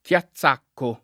Chiazzacco [ k L a ZZ# kko ] top. (Friuli)